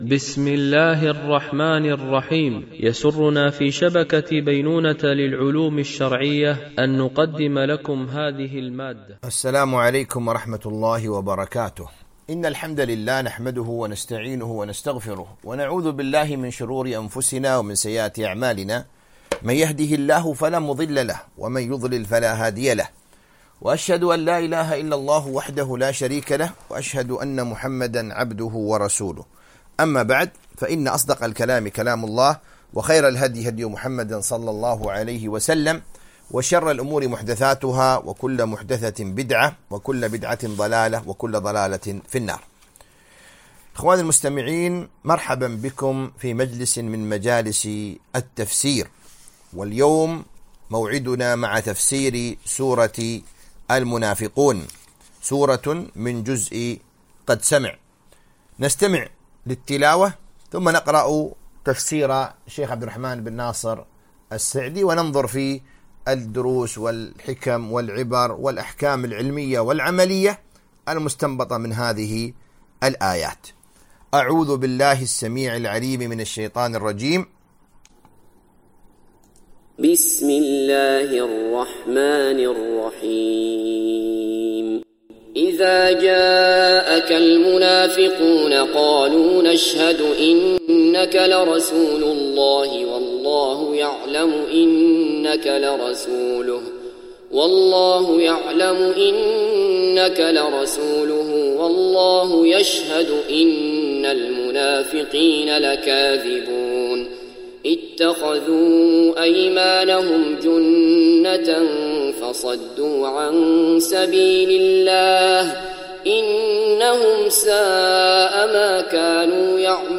تفسير جزء قد سمع ـ الدرس 13 ( سورة المنافقون )